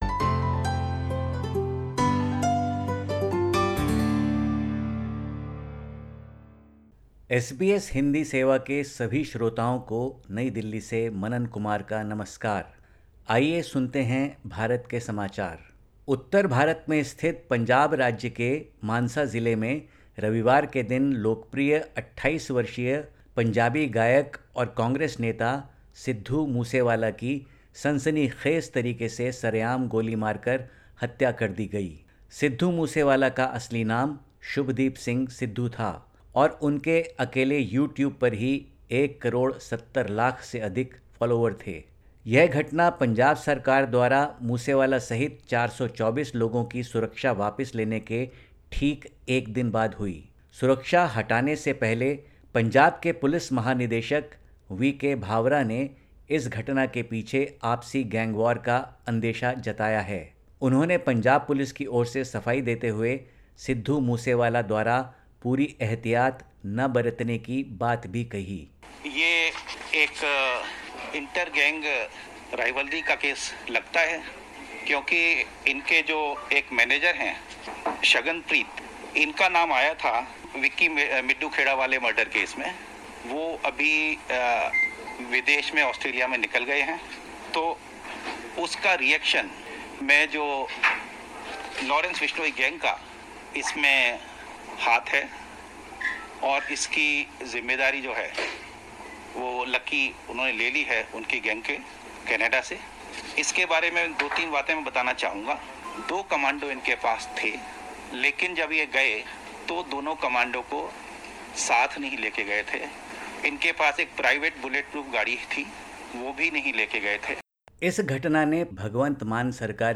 Listen to the latest SBS Hindi report in India. 30/05/2022